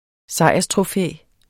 Udtale [ ˈsɑjˀʌstʁoˌfεˀ ]